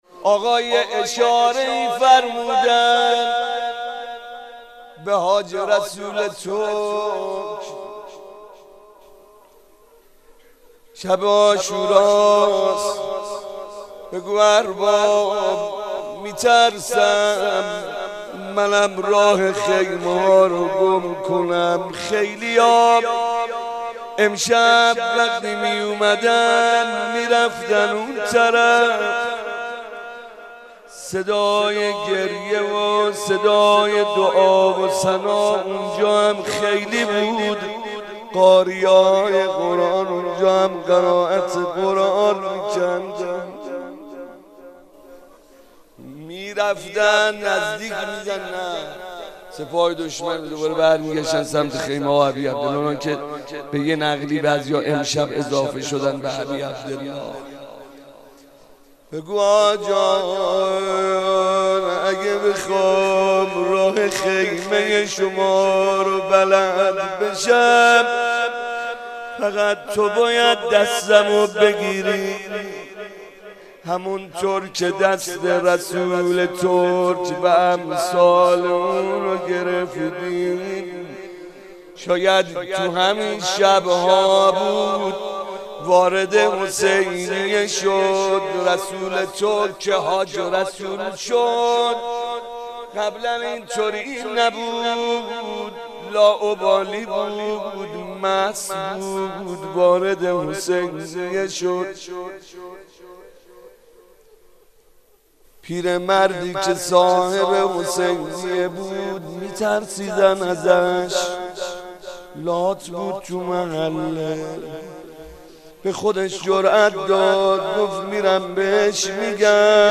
شب دوم محرم